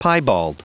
Prononciation du mot piebald en anglais (fichier audio)
Prononciation du mot : piebald